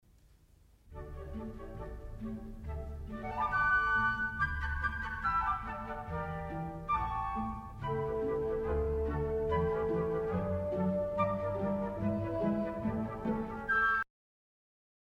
- tre flauti nello "
flauti.mp3